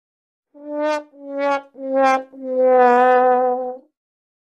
bruit de trompette
Reactions Soundboard
The bruit de trompette sound effect is widely used in meme videos, Instagram Reels, YouTube Shorts, gaming clips, and funny viral content.
bruit de trompette.mp3